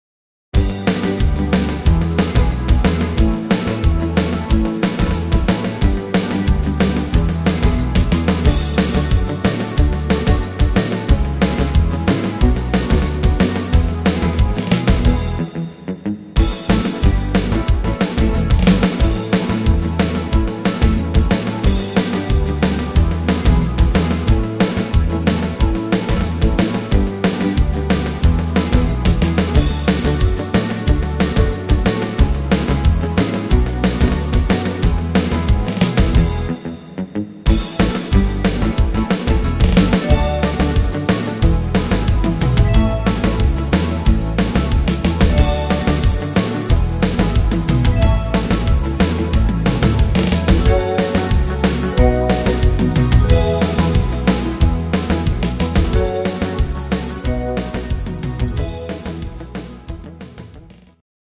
instr. Gitarre